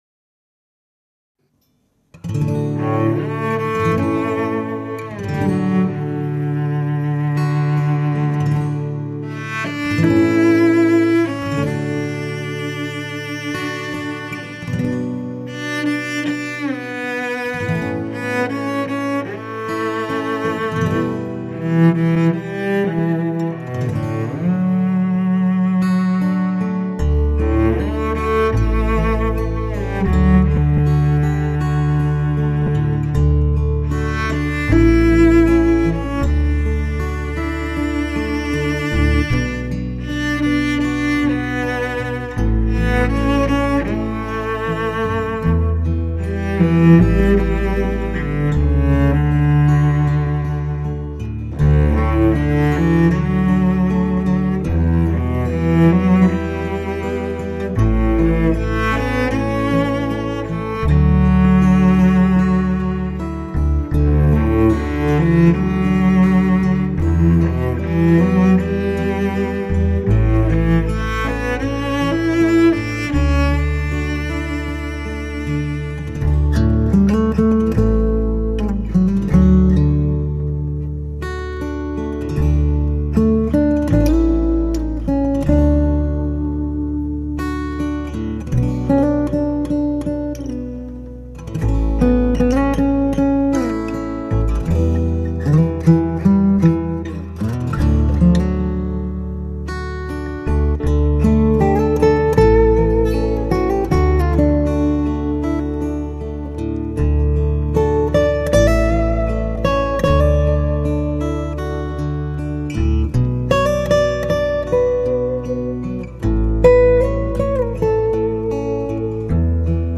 动用小提琴、结他、钢琴、长笛等西乐
歌曲开头的大提琴形态浮凸，擦弦之声松香四溢，甘畅的质感足以令发烧友遍体酥软。